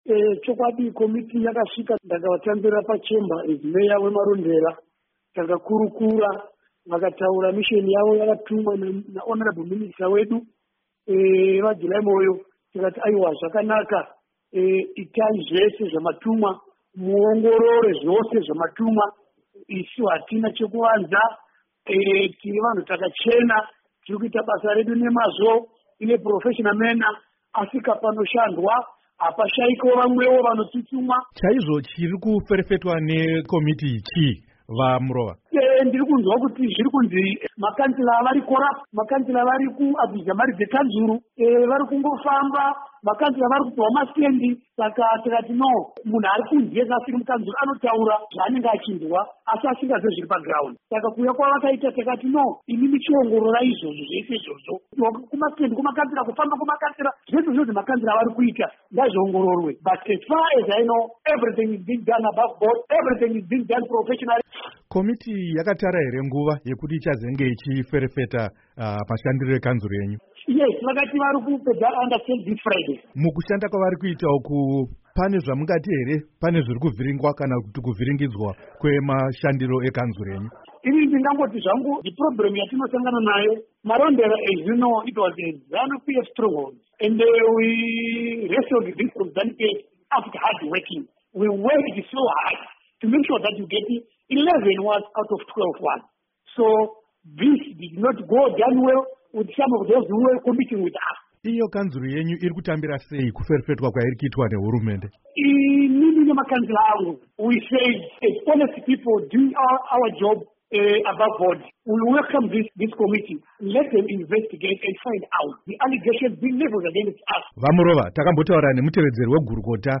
Hurukuro naVaChengetai Murova